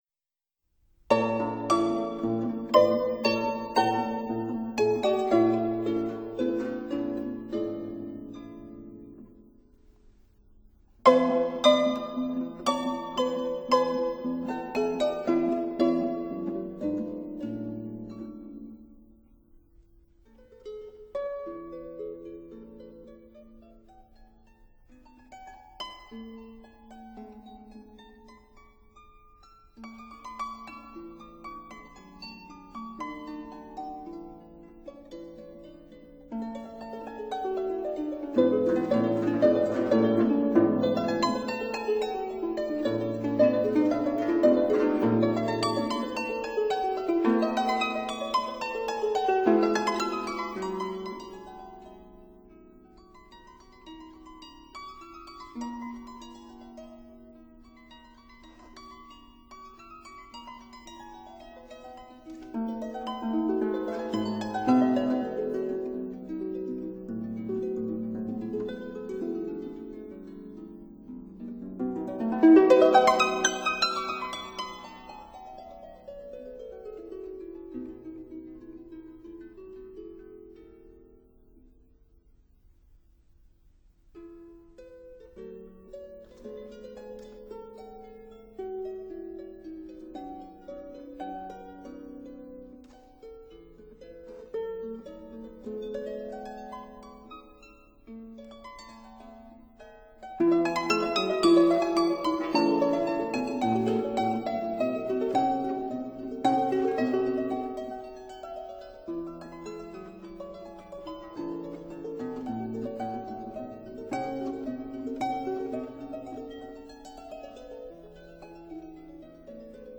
Duo for Piano and Harp in F major, Op. 11
(Period Instruments)